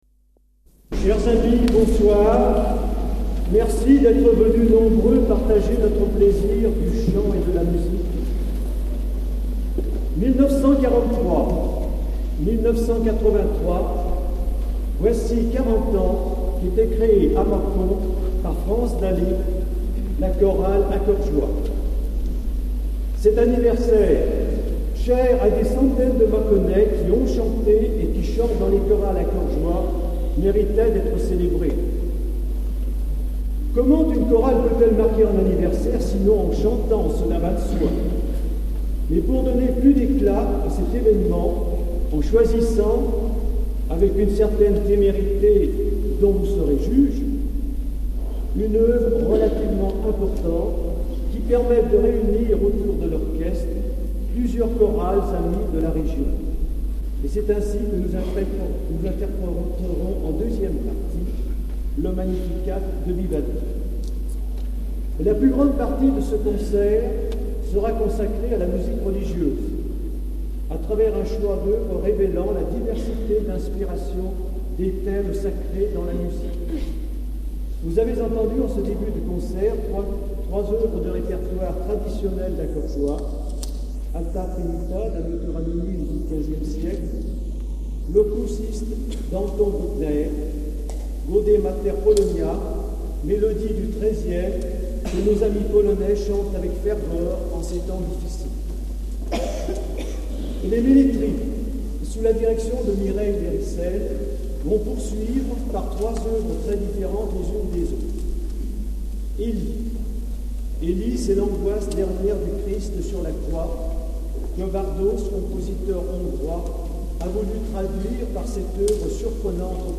Eglise St-Vincent MACON
Extraits du concert
1.Mot d'accueil